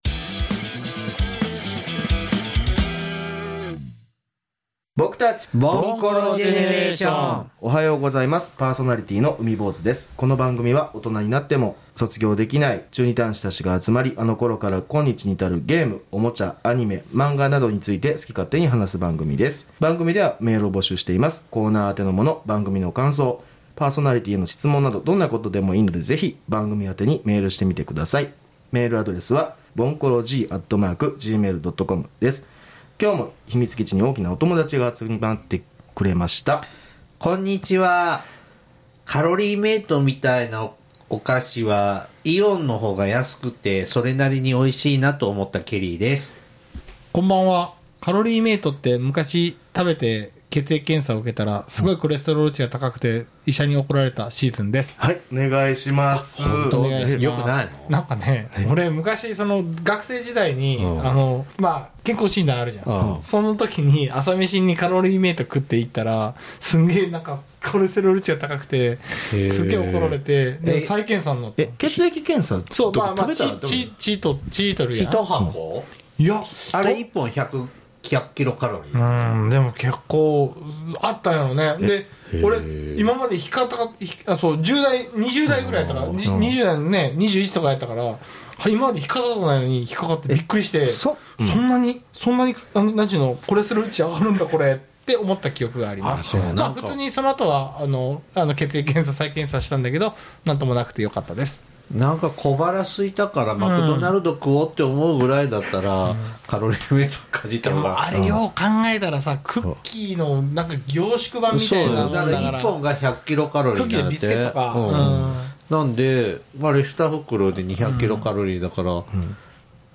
3人それぞれの思い出について話しています。